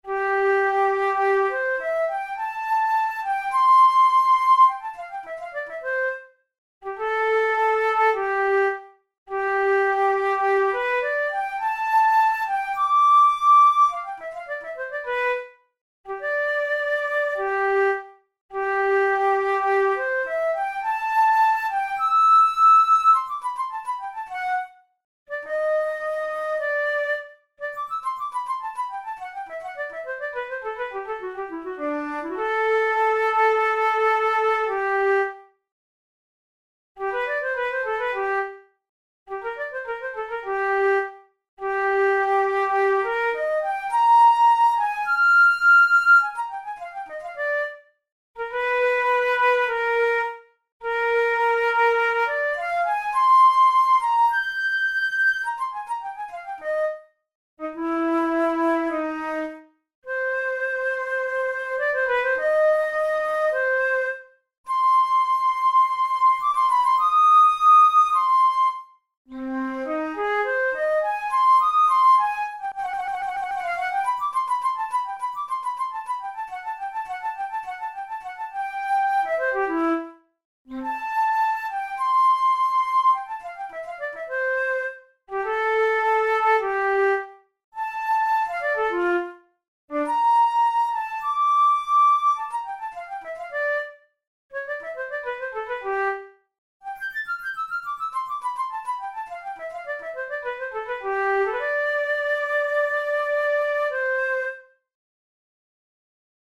Tune of the Day: Study in C major by Kummer
Categories: Classical Etudes Written for Flute Difficulty: easy